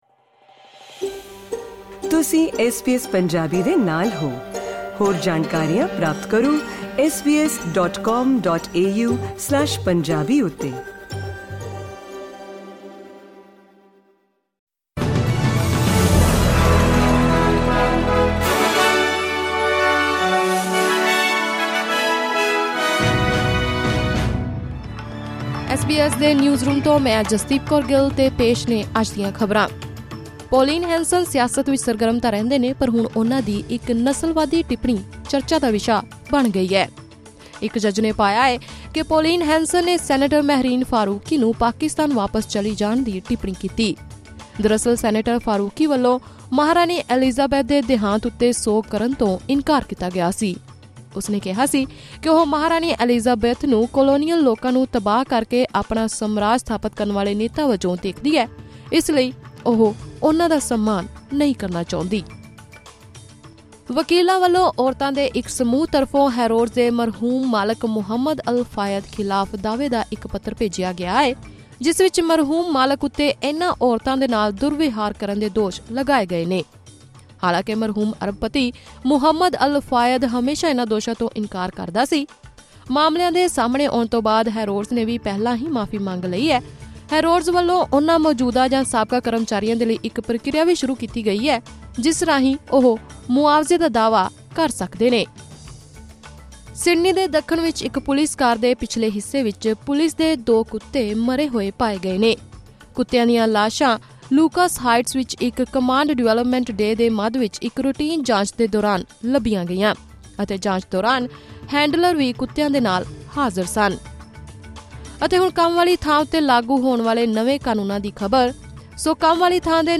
ਐਸ ਬੀ ਐਸ ਪੰਜਾਬੀ ਤੋਂ ਆਸਟ੍ਰੇਲੀਆ ਦੀਆਂ ਮੁੱਖ ਖ਼ਬਰਾਂ: 1 ਨਵੰਬਰ 2024